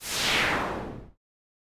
snd_vaporized.wav